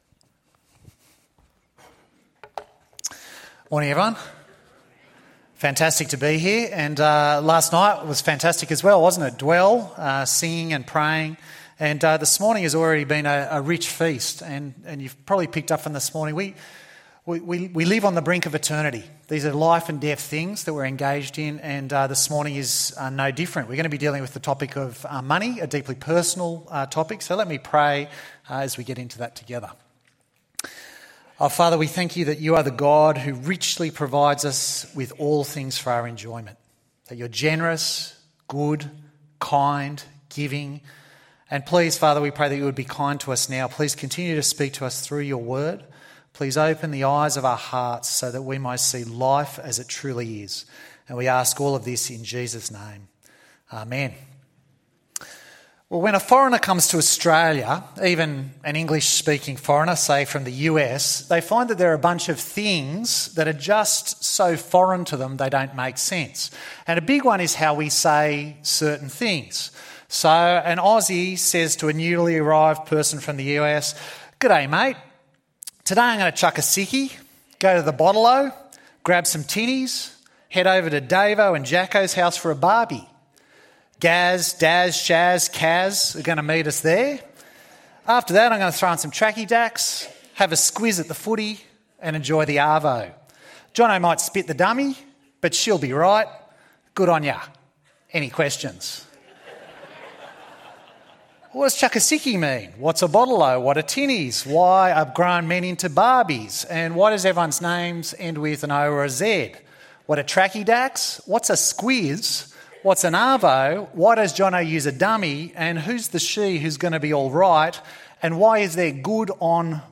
Money and Eternity ~ EV Church Sermons Podcast